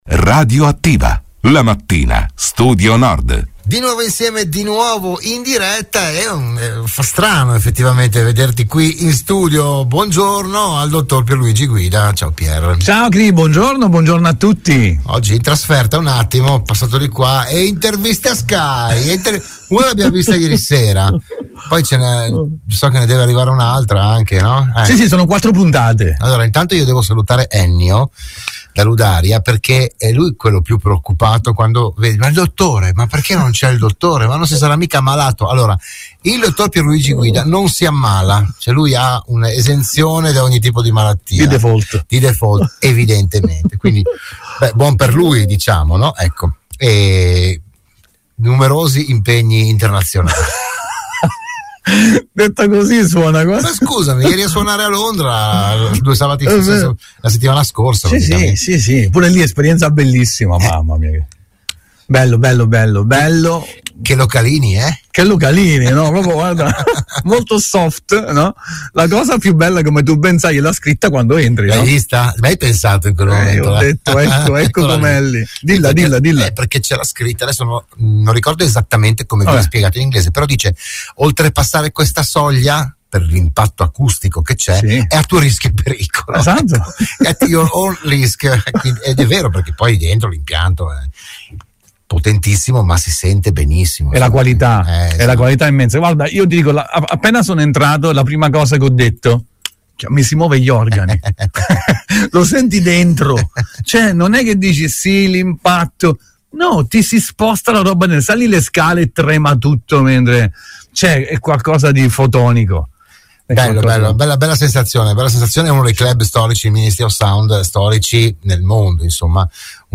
Nuova puntata per “Buongiorno Dottore”, la numero 150, il programma di prevenzione e medicina in onda all’interno della trasmissione di Radio Studio Nord “RadioAttiva”.